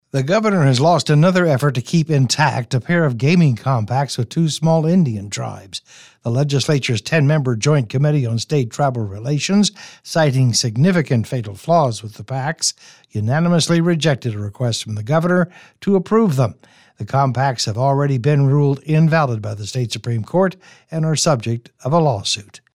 details from Radio Oklahoma’s